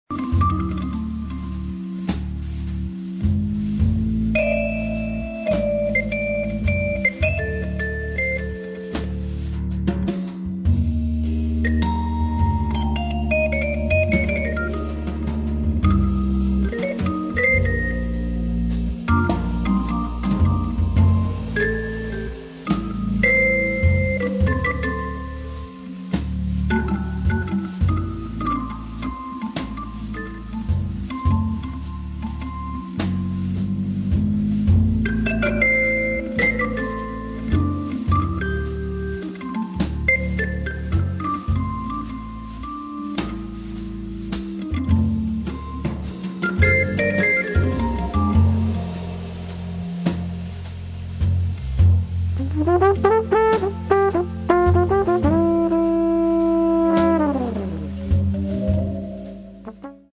[ JAZZ ]